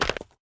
Free Fantasy SFX Pack
Chopping and Mining
mine 5.ogg